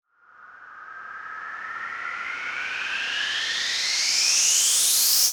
Crashes & Cymbals
edm-crash-rvs-02.wav